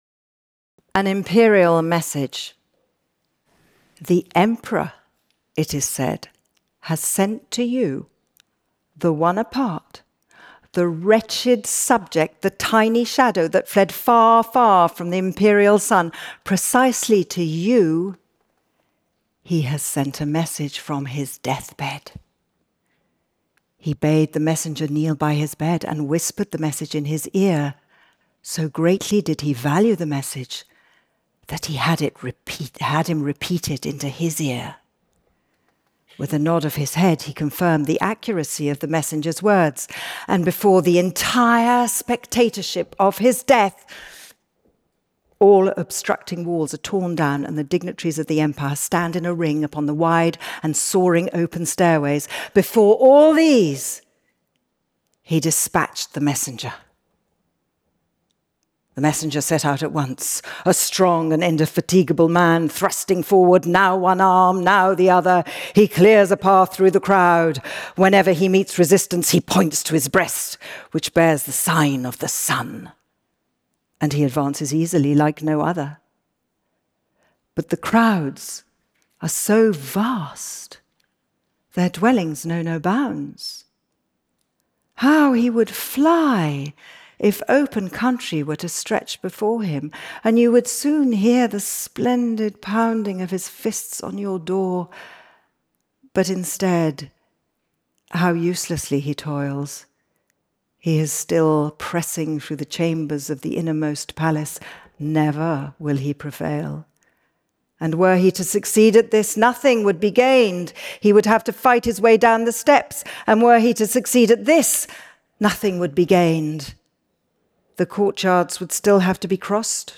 Kristin Scott Thomas Reads Kafka